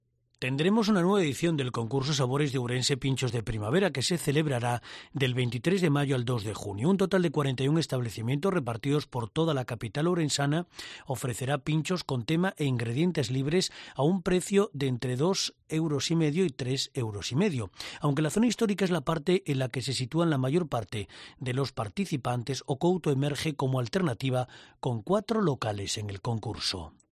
Presentación del concurso en el Concello de Ourense